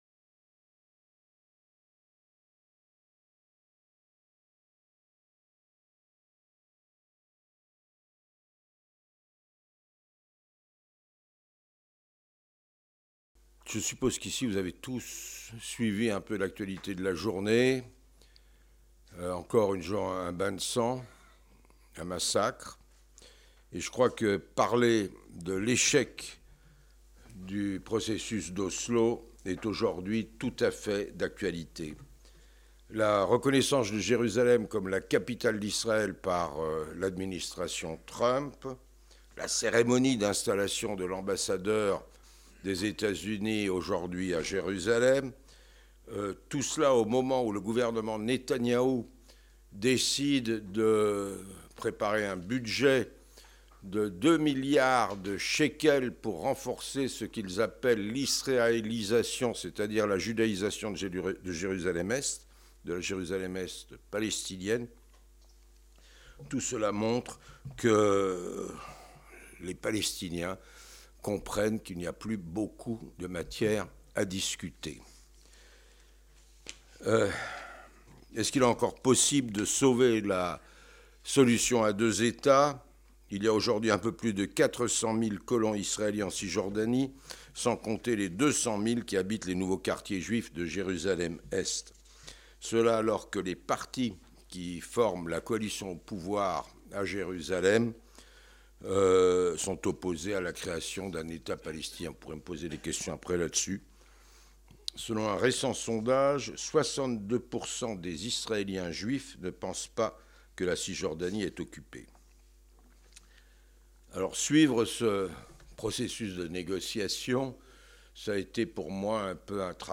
Conférences Penser global Correspondant de France Télévisions à Jérusalem de 1981 à 2015, Charles Enderlin a été le témoin privilégié de moments historiques du conflit israélo-palestinien. Au cours de trois conférences exceptionnelles, il revient sur la question complexe des relations israélo-palestiniennes.